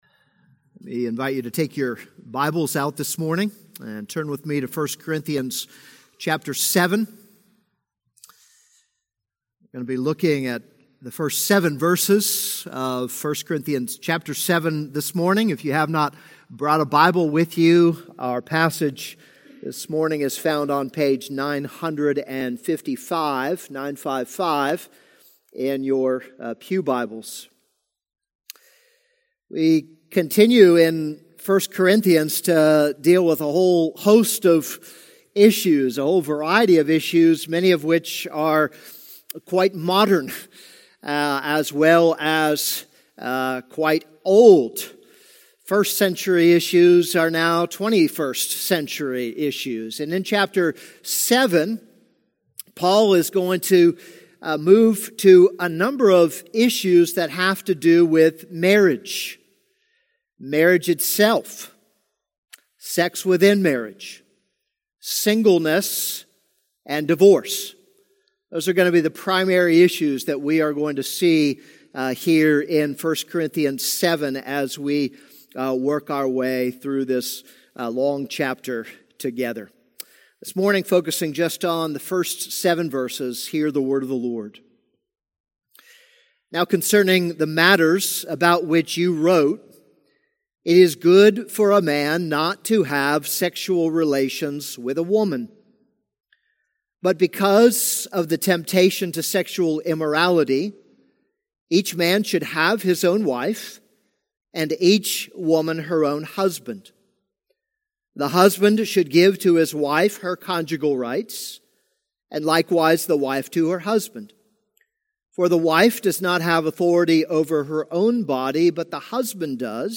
This is a sermon on 1 Corinthians 7:1-7.